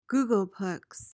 PRONUNCIATION:
(GOO-guhl-pleks)